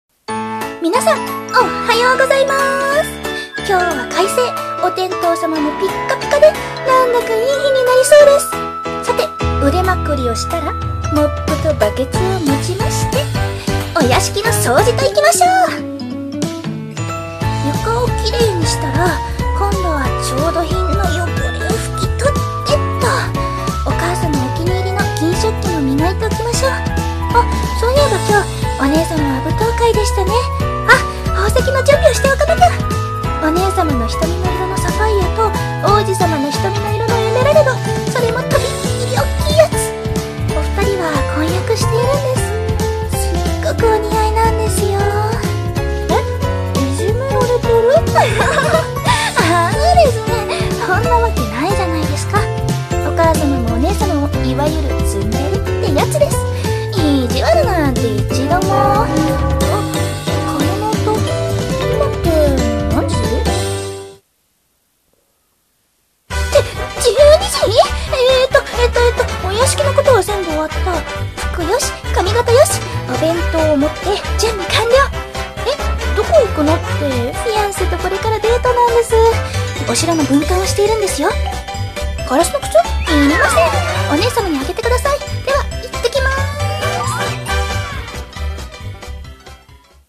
【声劇】灰被りの日常